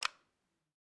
FlashlightClose.mp3